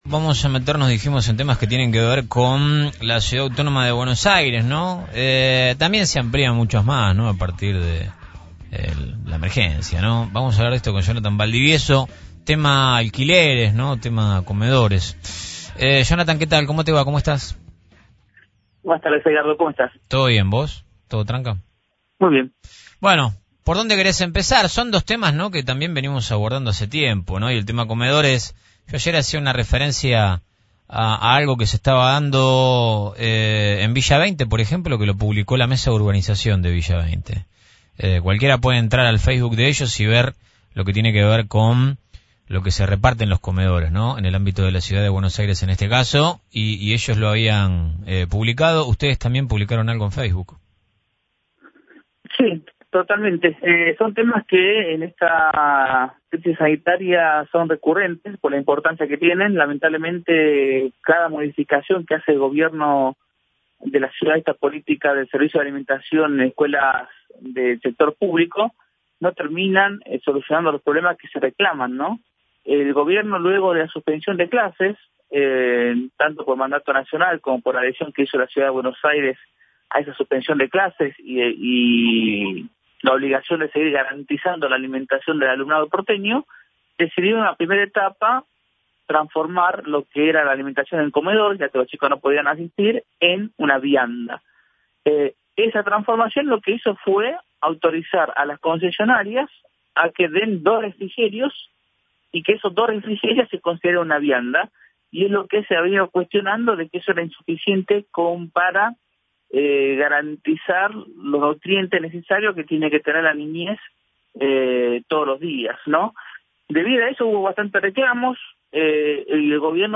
En diálogo con FRECUENCIA ZERO